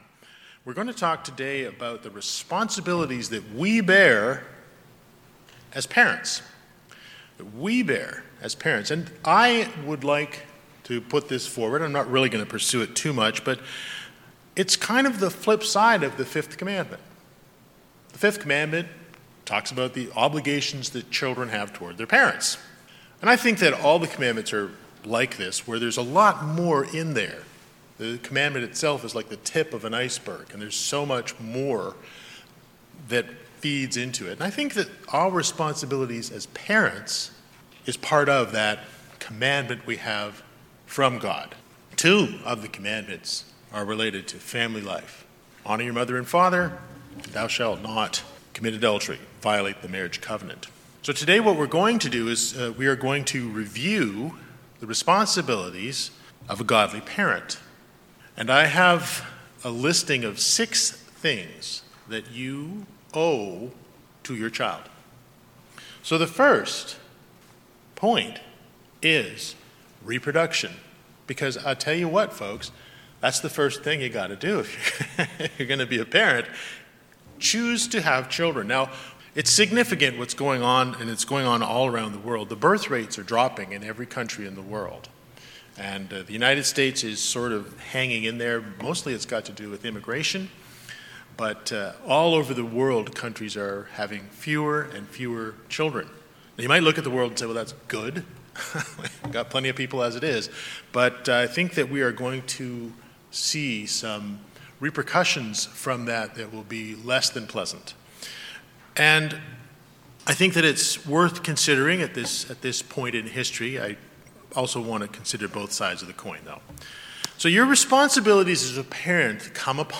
This message review 6 responsibilities parents have according to the bible.